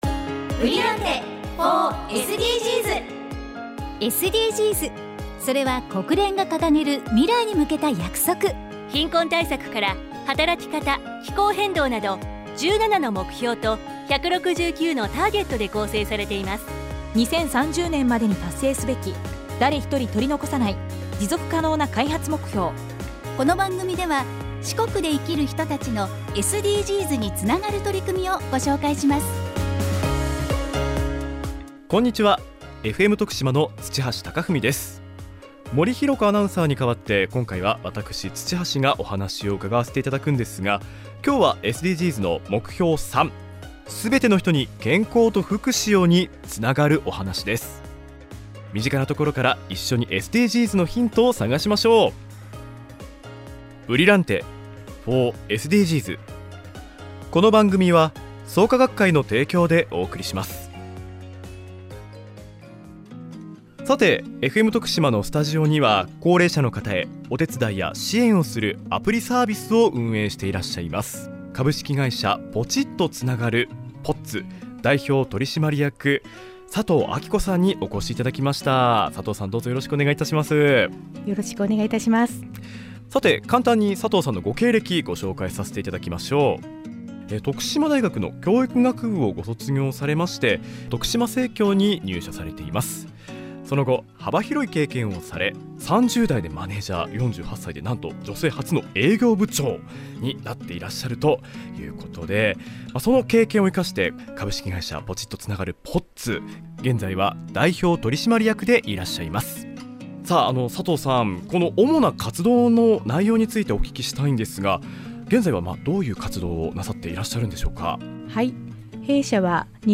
①FMラジオ出演（10月7日）
トークは、ほんっと苦手なんですが、プロのアナウンサーのリードってすごいですよね。